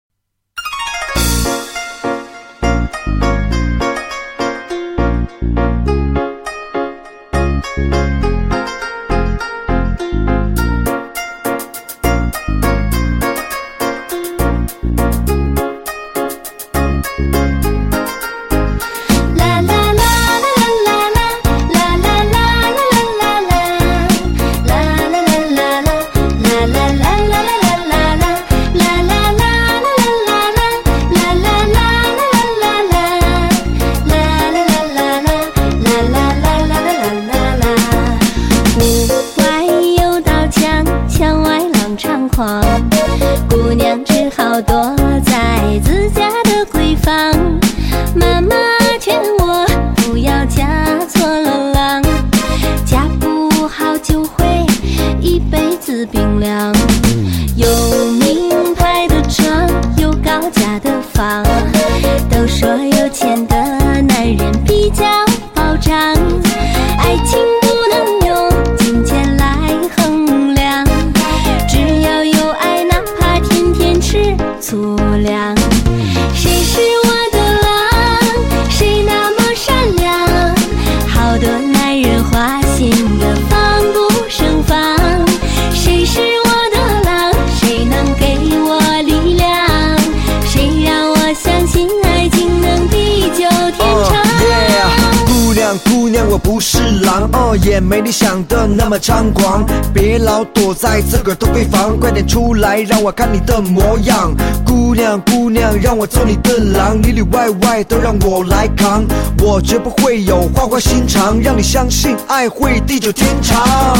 [流行音乐]